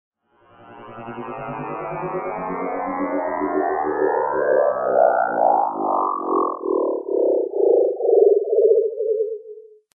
На этой странице собраны звуки, которые ассоциируются с инопланетянами и пришельцами: странные сигналы, электронные помехи, \
Шум посадки НЛО